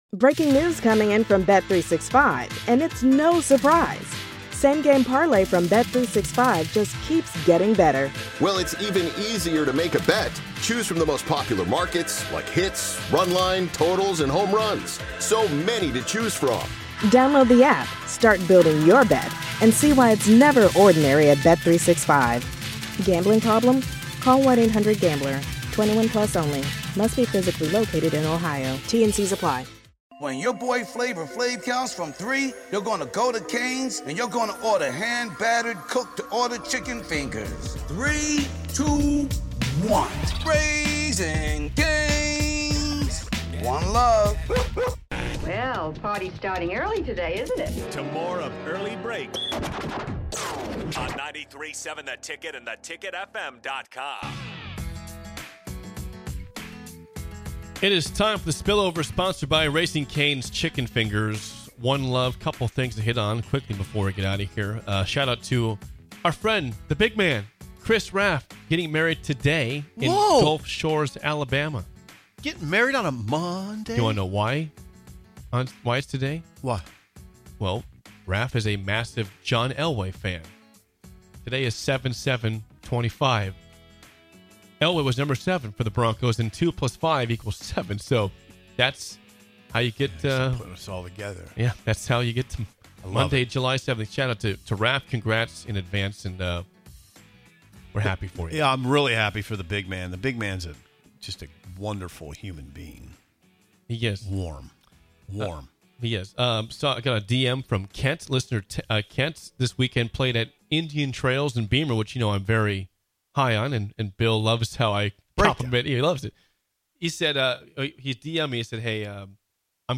Join these three goofballs from 6-8am every weekday morning for the most upbeat and energetic morning show you'll ever experience. Grab a cup of coffee, turn up the volume, and imagine you're right alongside them in studio!!